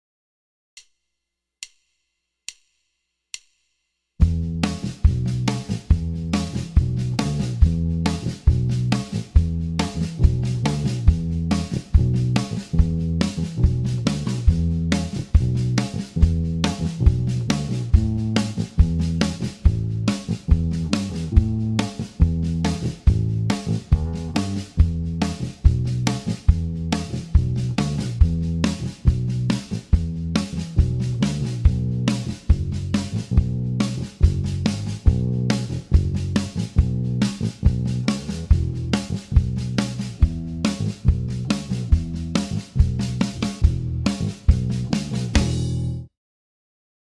slow speed version of the jam track here